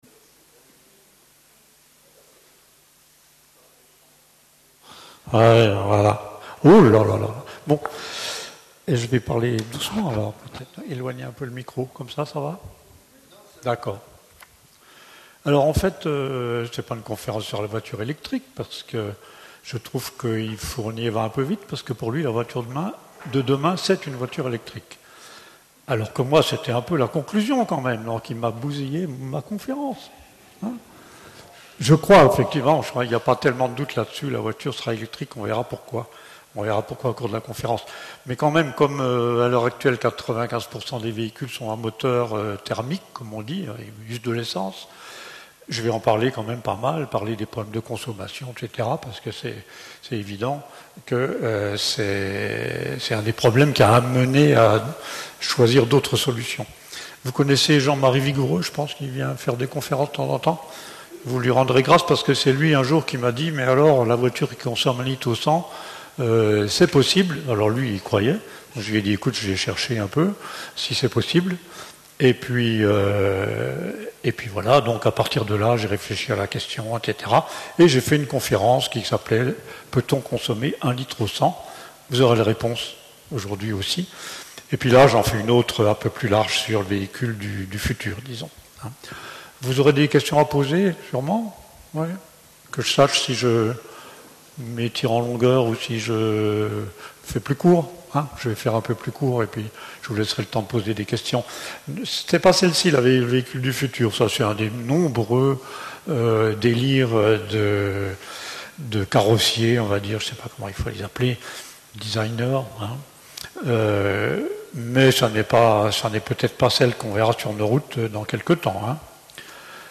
Développement durable : la voiture du futur Conférences | Université pour Tous de Bourgogne
Lieu de la conférence Maison des syndicats 2 rue du Parc 71100 Chalon sur Saône Vidéos Audio Enregistrement la Voiture du futur Contenu réservé aux adhérents Test pour video